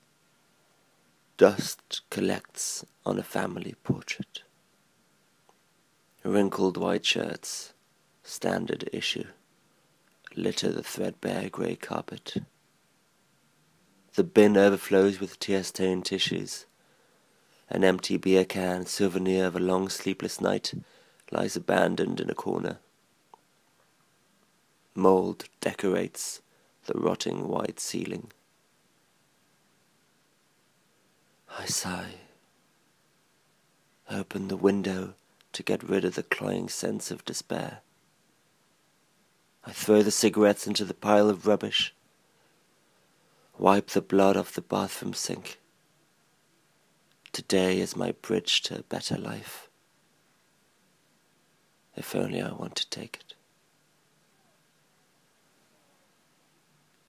Poème